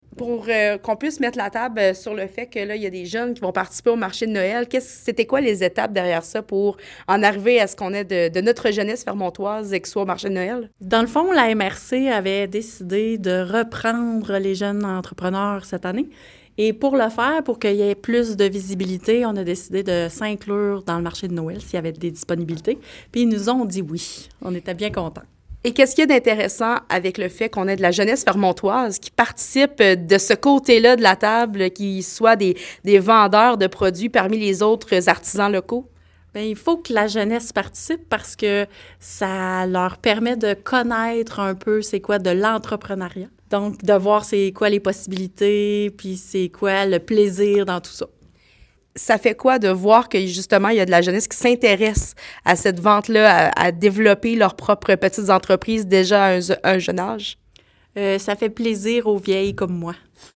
Courte entrevue